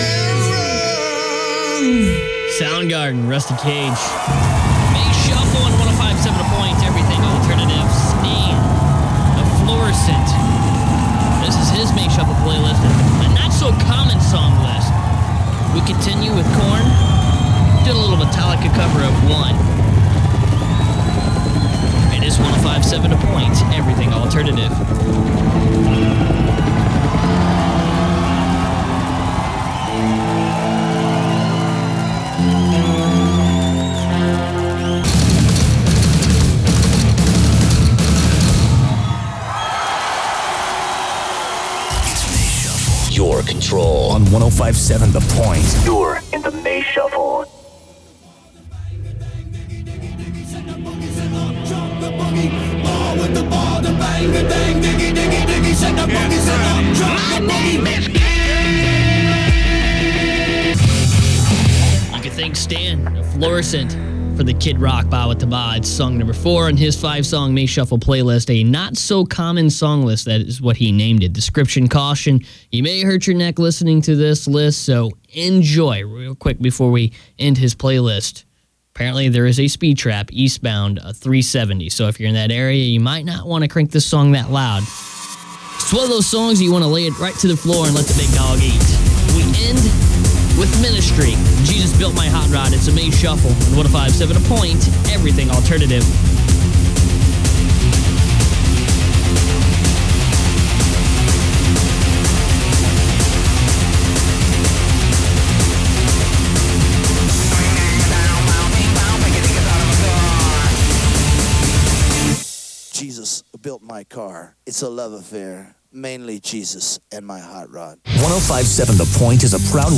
KPNT Unknown Aircheck · St. Louis Media History Archive